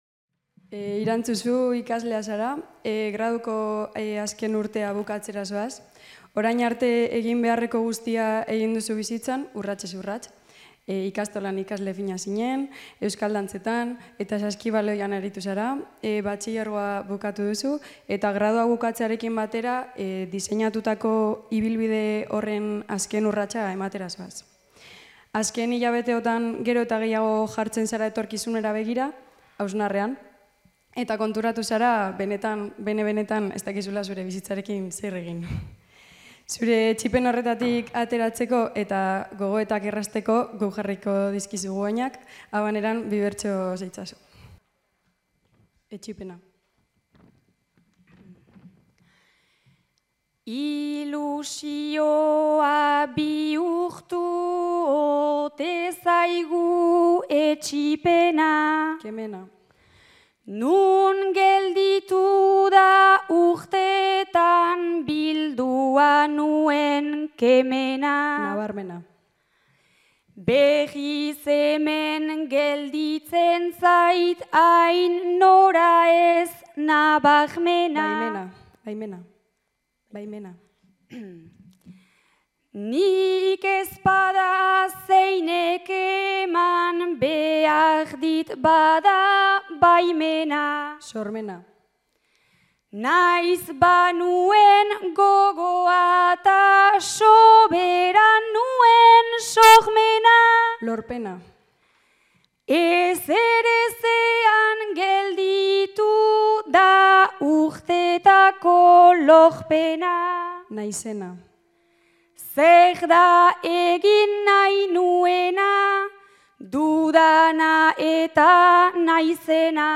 Bertso jaialdia